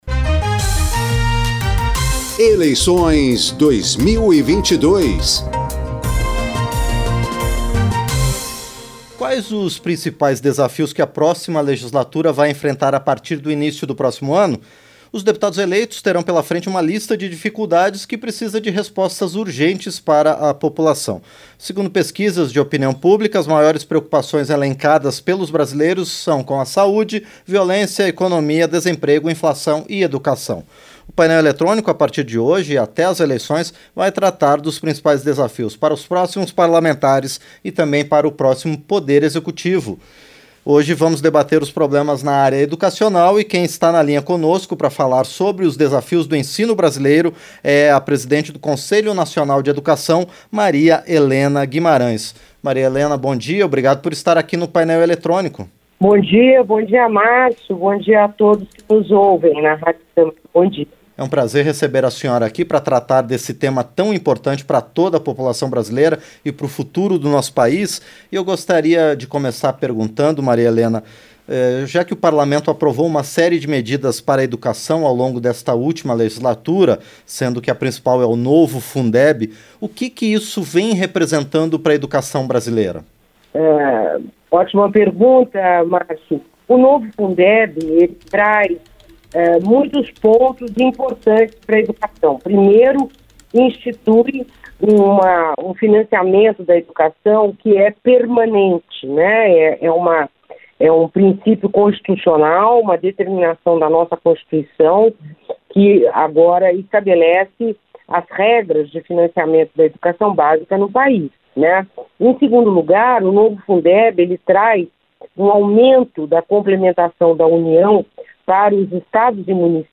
Entrevista - Maria Helena Guimarães